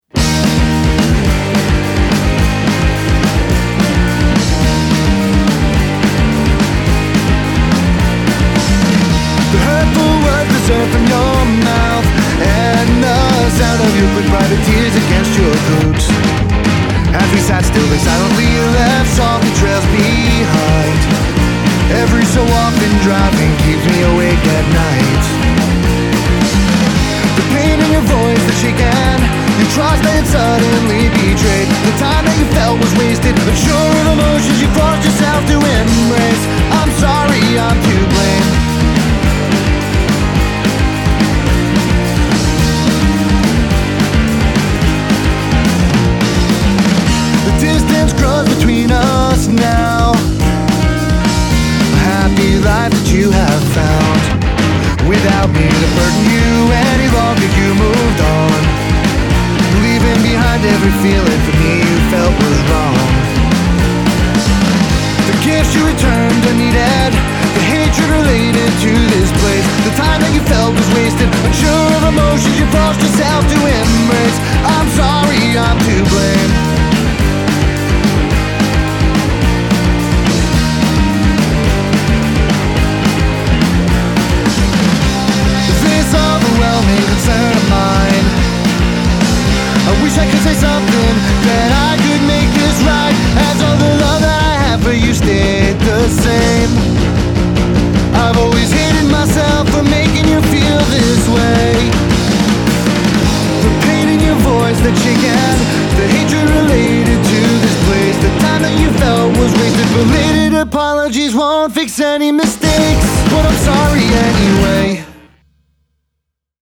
Vocals, Guitar, Drums